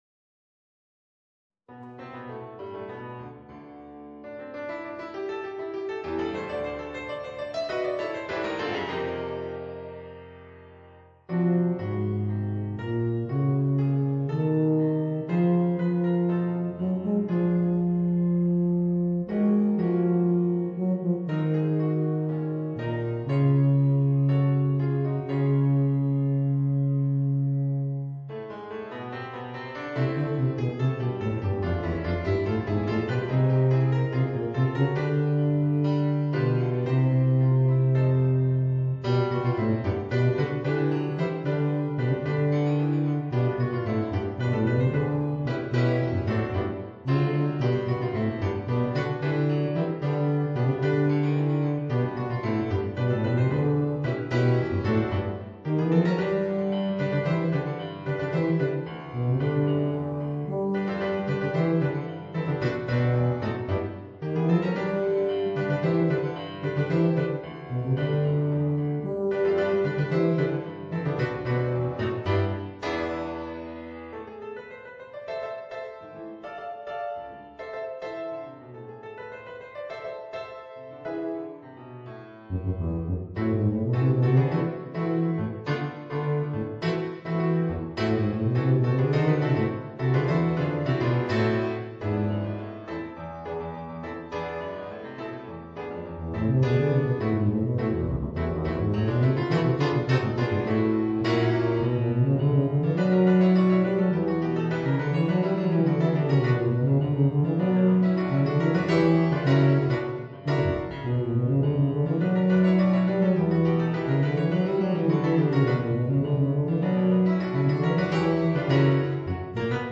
Voicing: Tuba and Piano